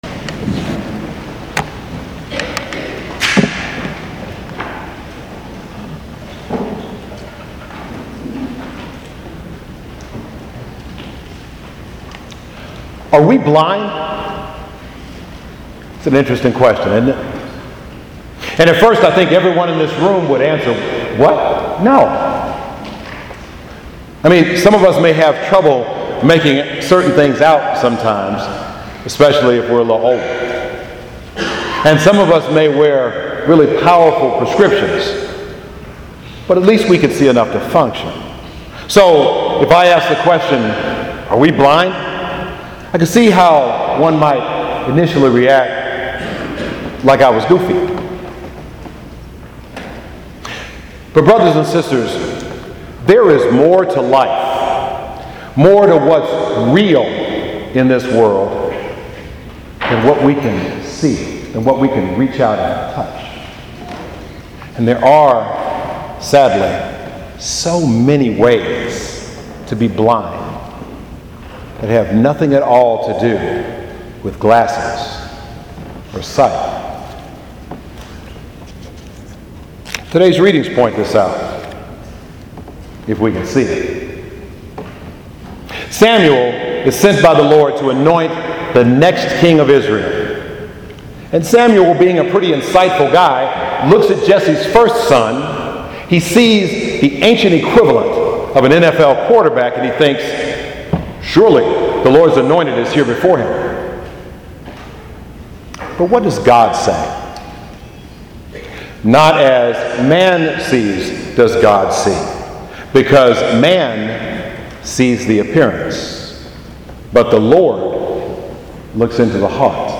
Homily: 4th Sunday of Lent – “Are We Blind?” (Cycle A readings)